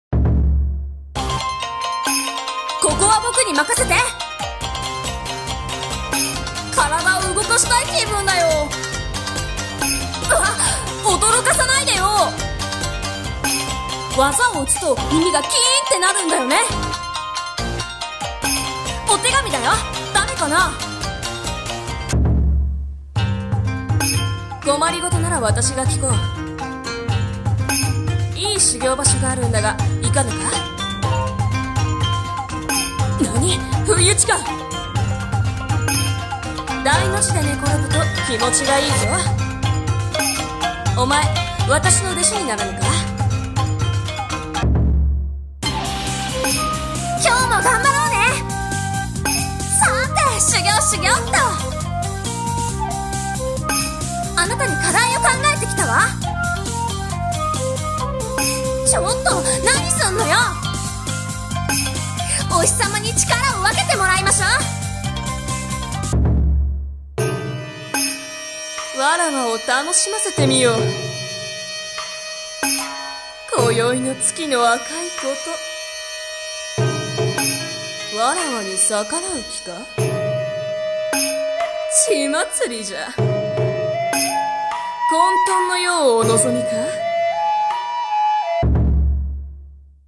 【演じ分け台本】妖怪格ゲーアプリ 女声/少年声用/後編【和風】